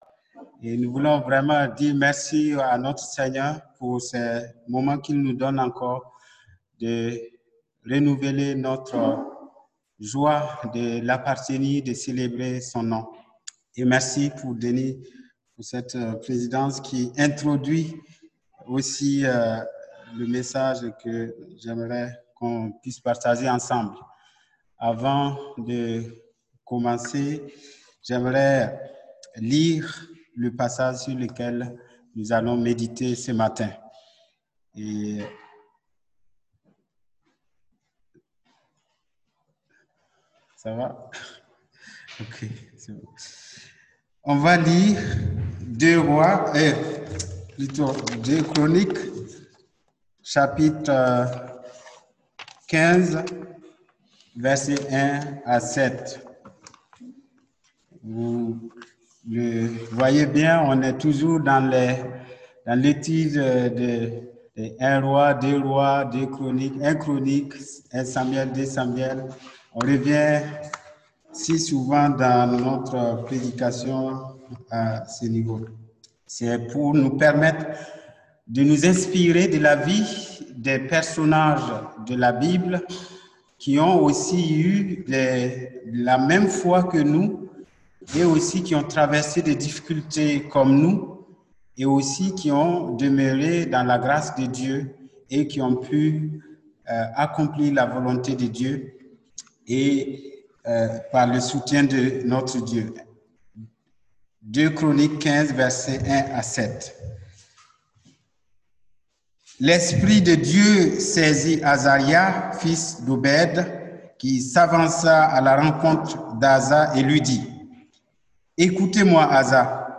2 chronicle 7:14 Type De Service: Messages du dimanche j’ai aimé Jacob